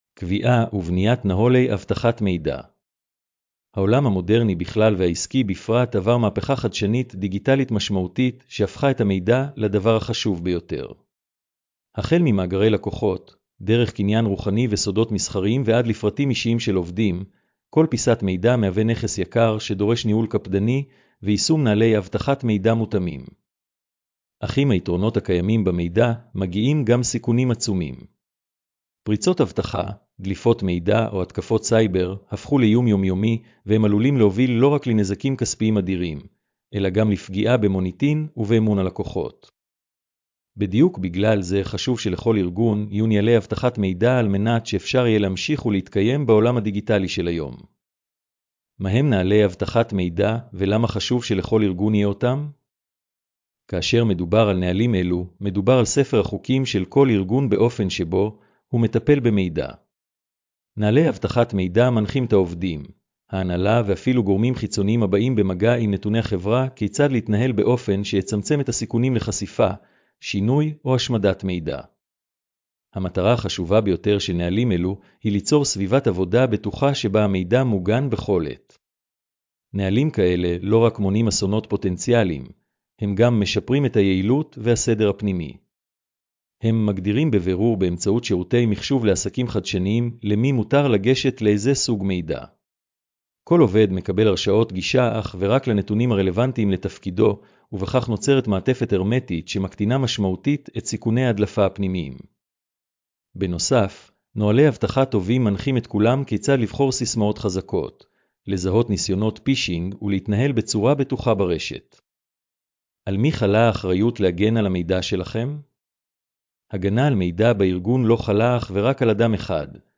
הקראת המאמר לאנשים עם מוגבלות: